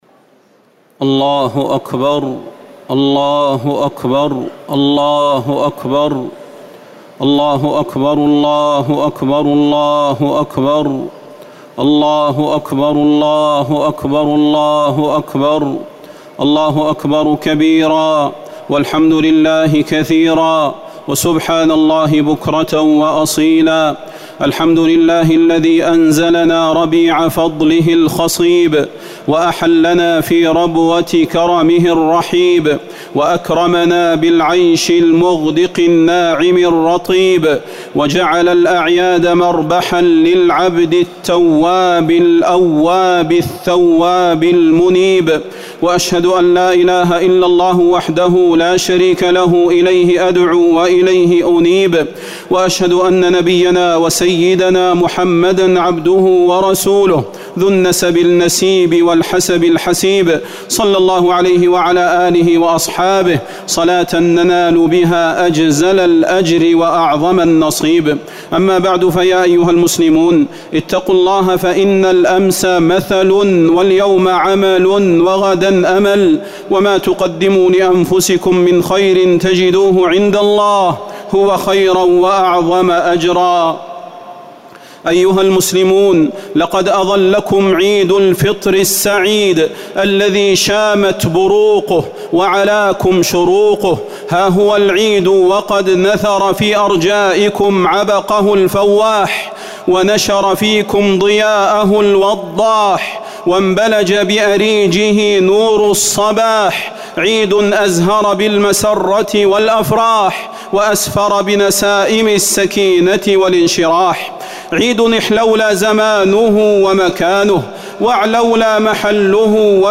خطبة عيد الفطر - المدينة - الشيخ صلاح البدير - الموقع الرسمي لرئاسة الشؤون الدينية بالمسجد النبوي والمسجد الحرام
تاريخ النشر ١ شوال ١٤٤٠ هـ المكان: المسجد النبوي الشيخ: فضيلة الشيخ د. صلاح بن محمد البدير فضيلة الشيخ د. صلاح بن محمد البدير خطبة عيد الفطر - المدينة - الشيخ صلاح البدير